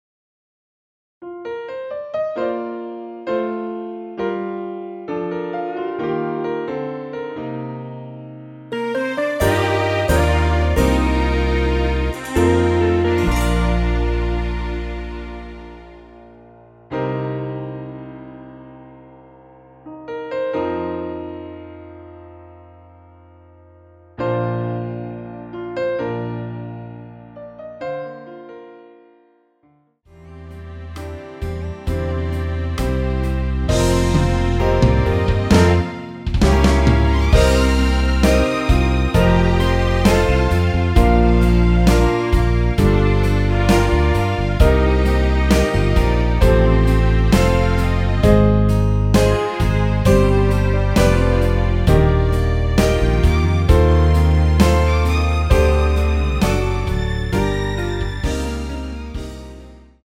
원키에서(-6)내린 MR입니다.
Bb
앞부분30초, 뒷부분30초씩 편집해서 올려 드리고 있습니다.
중간에 음이 끈어지고 다시 나오는 이유는